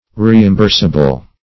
Search Result for " reimbursable" : The Collaborative International Dictionary of English v.0.48: Reimbursable \Re`im*burs"a*ble\ (r?`?m*b?rs"?*b'l), a. [CF. F. remboursable.]